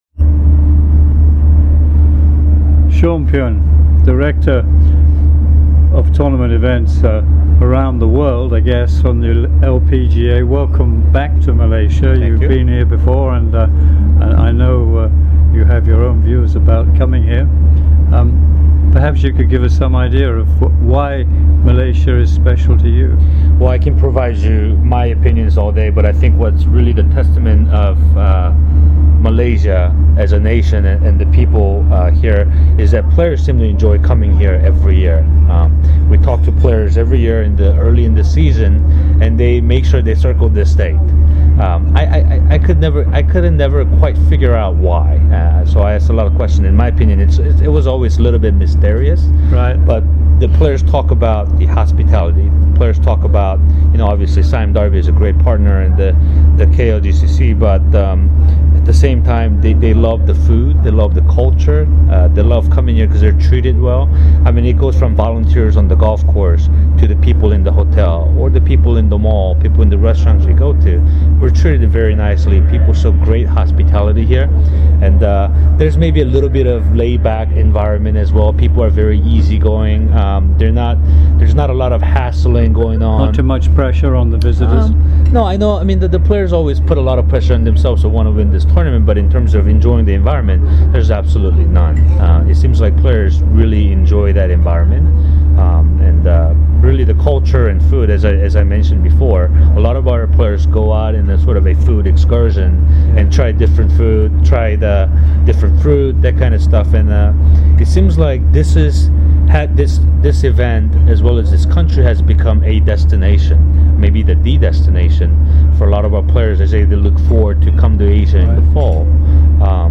MGTA interviews